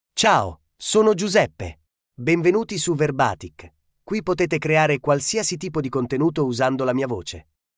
GiuseppeMale Italian AI voice
Giuseppe is a male AI voice for Italian (Italy).
Voice sample
Listen to Giuseppe's male Italian voice.
Male
Giuseppe delivers clear pronunciation with authentic Italy Italian intonation, making your content sound professionally produced.